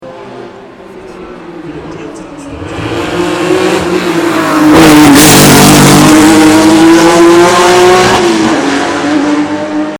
Porsche Carrera 6 (1966) bei der Arosa ClassicCar 2010
Tags:TonMotorensoundAuspuffgeräuschSportwagenTop-5
Porsche_Carrera_6_-_Arosa_ClassicCar_2010_-_Zieleinfahrt.mp3